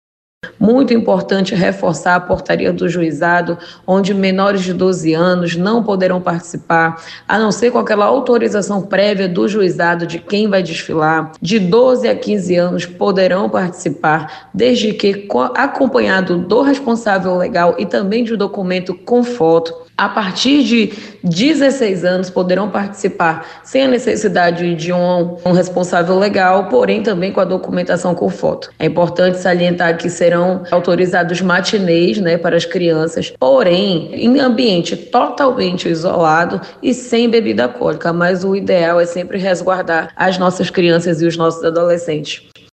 A secretária Jussara Pedrosa destaca a importância de ficar atento a portaria voltada a participação de menores em eventos carnavalescos.